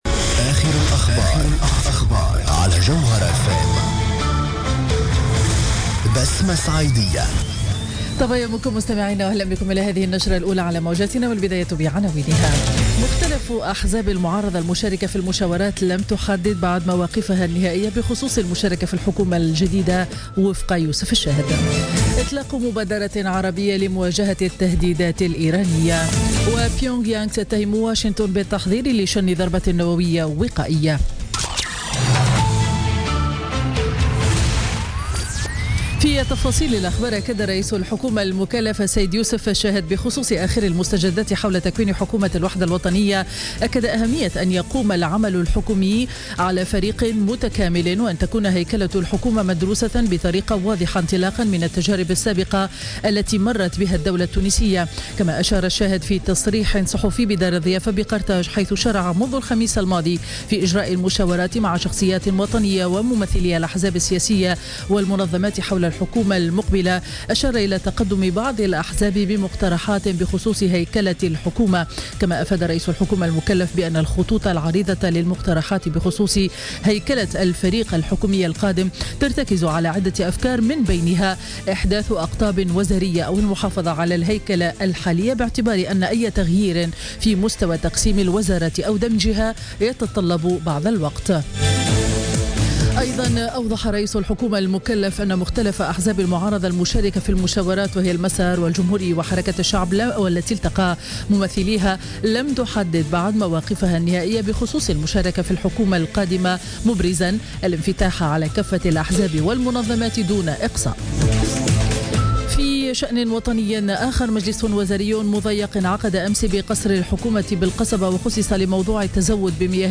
نشرة أخبار السابعة صباحا ليوم الأحد 7 أوت 2016